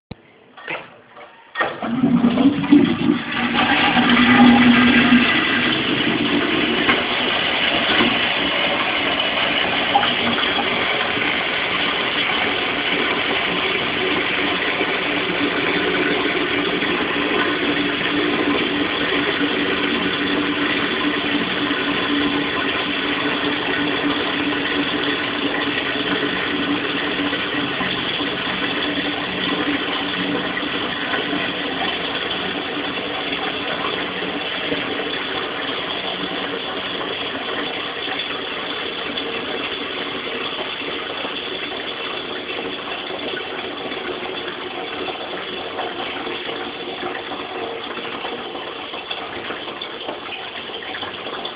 - Portal de Educación de la Junta de Castilla y León - Cisterna de WC
Descripción: Sonido de una cisterna de Wc.